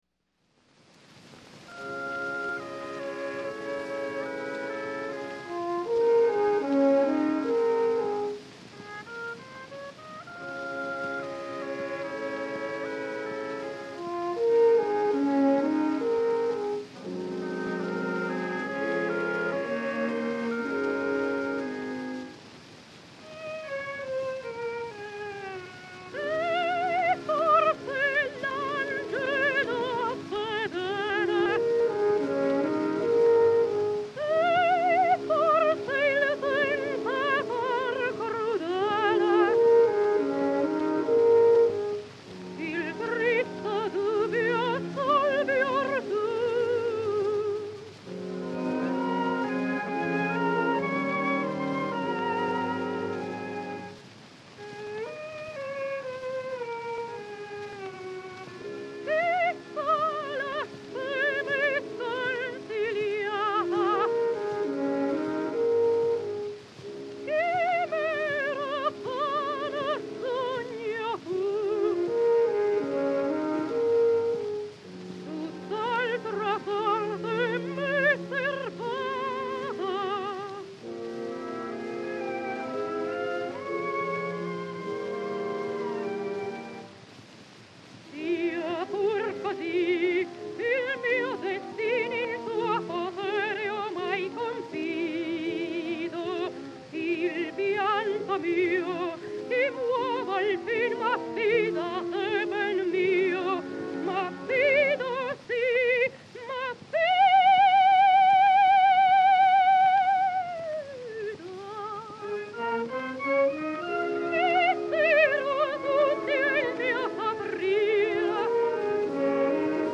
Клаудия Муцио (сопрано)